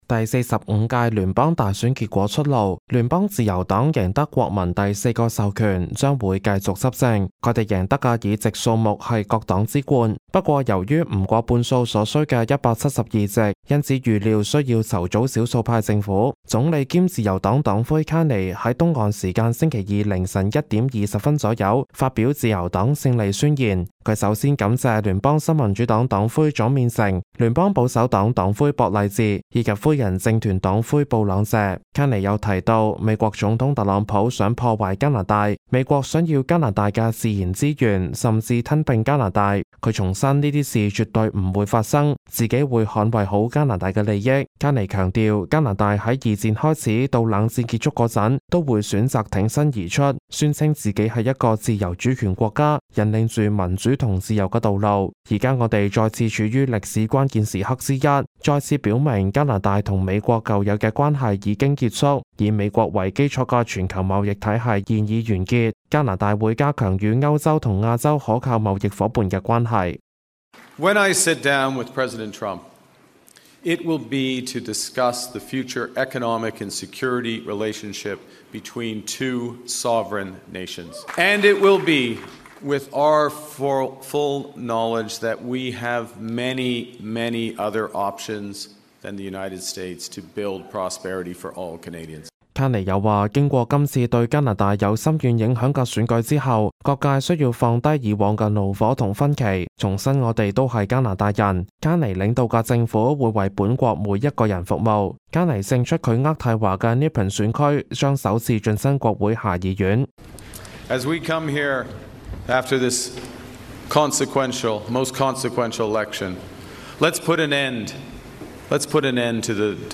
news_clip_23350.mp3